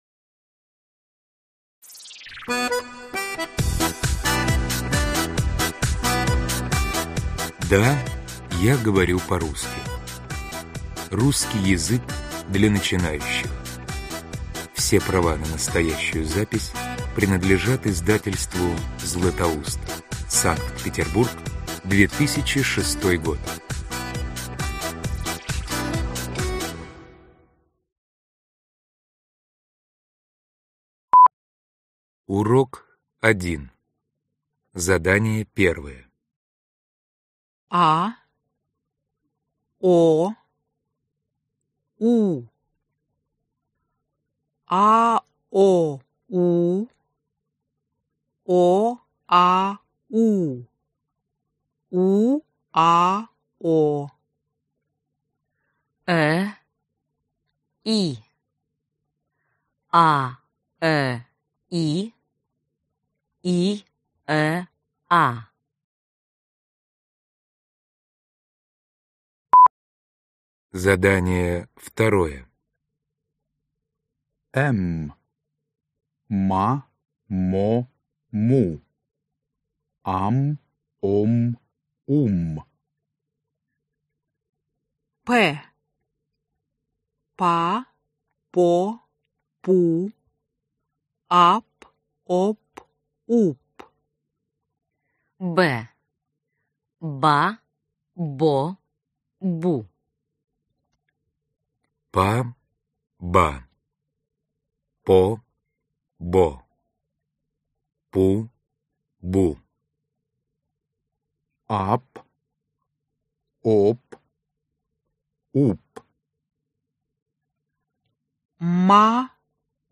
Аудиокнига Да, я говорю по-русски | Библиотека аудиокниг